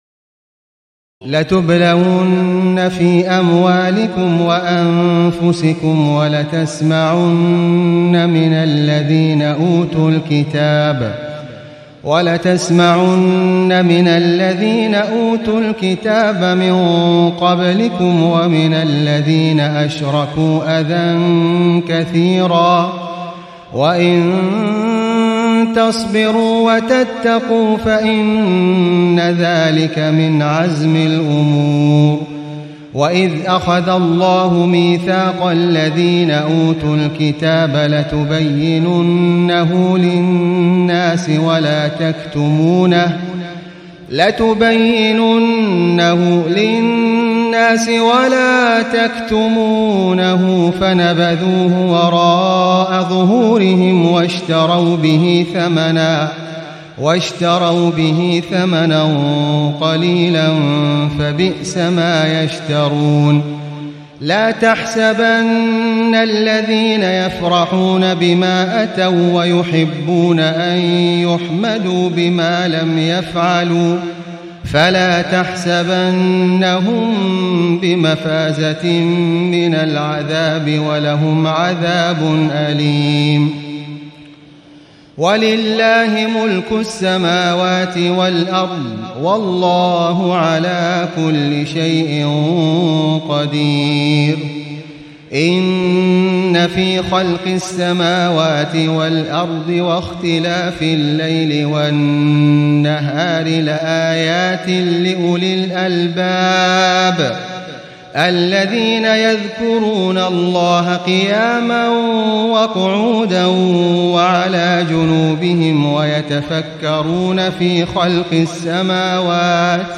تهجد ليلة 24 رمضان 1437هـ من سورتي آل عمران (186-200) و النساء (1-24) Tahajjud 24 st night Ramadan 1437H from Surah Aal-i-Imraan and An-Nisaa > تراويح الحرم المكي عام 1437 🕋 > التراويح - تلاوات الحرمين